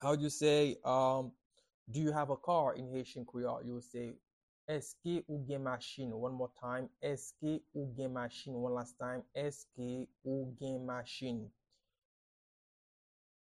a native Haitian voice-over artist can be heard in the recording here:
Do-you-have-a-car-in-Haitian-Creole-–-Eske-ou-gen-machin-pronunciation-by-a-Haitian-teacher.mp3